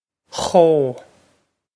Chomh khoh
Pronunciation for how to say
This is an approximate phonetic pronunciation of the phrase.